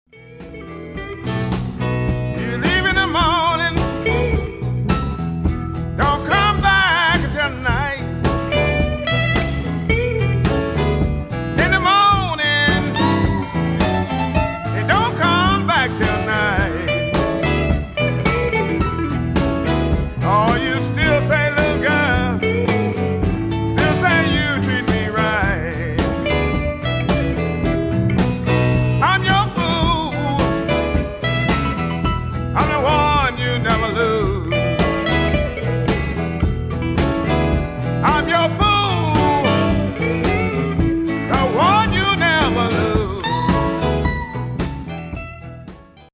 vocals and bass
harp
piano
drums.